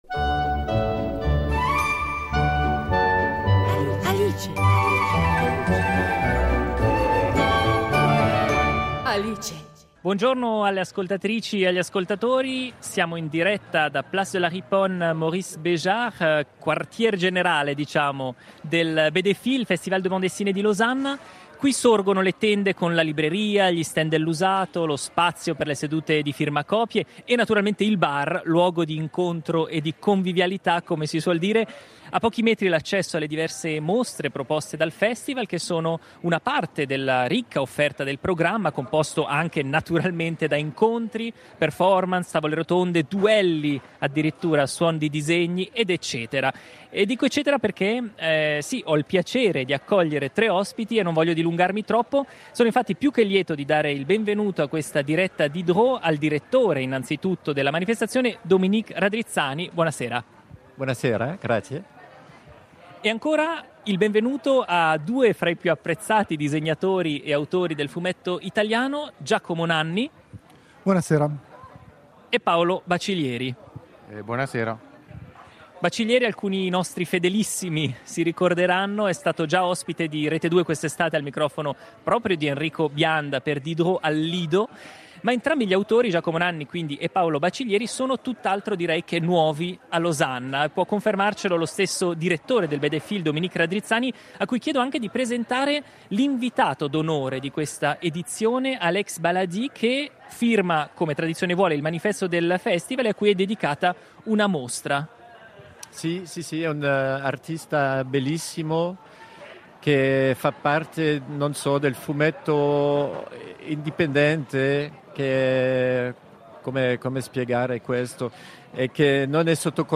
in occasione della 15esima edizione del BD-FIL, festival internazionale del fumetto di Losanna, svoltasi nel settembre del 2019